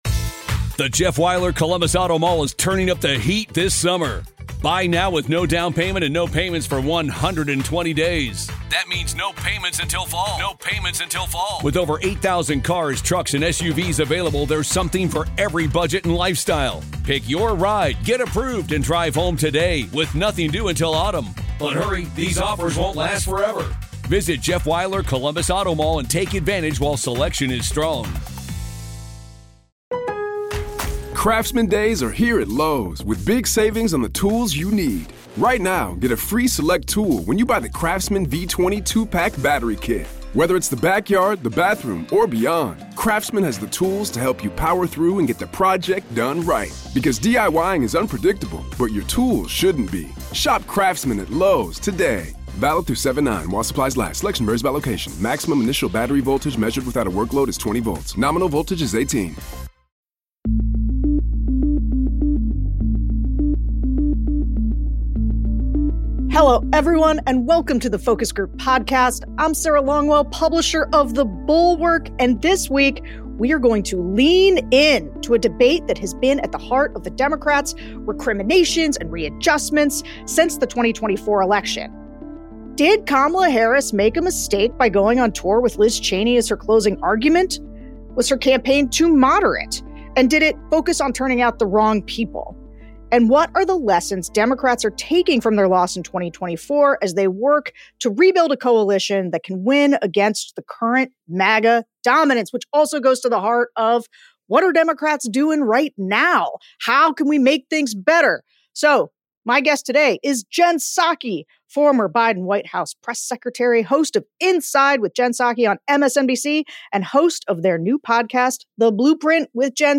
They also talk to some of 2024's non-voters, who have some thoughts on how Democrats should be fighting back right now. Jen Psaki, host of MSNBC's Inside with Jen Psaki and the podcast The Blueprint with Jen Psaki , joins the show.